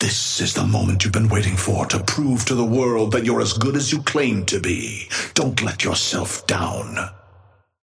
Amber Hand voice line - This is the moment you've been waiting for to prove to the world you're as good as you claim to be.
Patron_male_ally_chrono_start_05.mp3